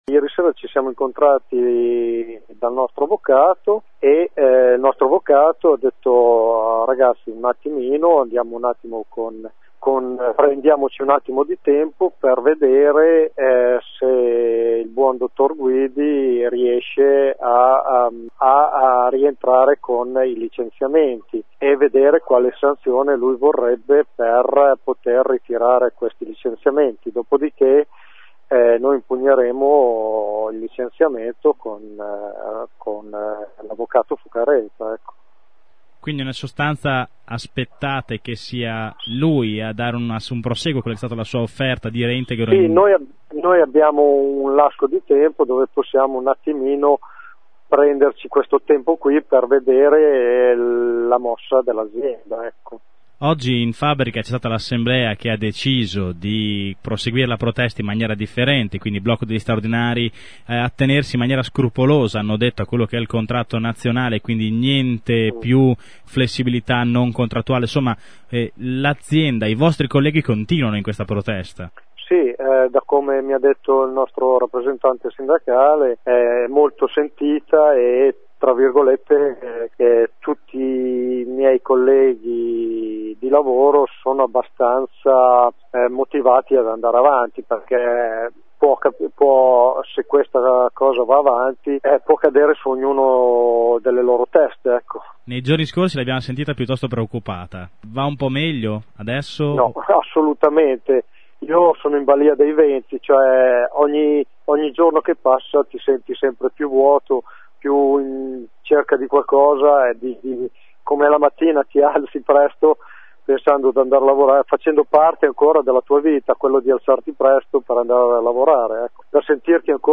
Ascolta uno dei lavoratori licenziati